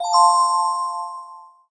bright_bell_chime.ogg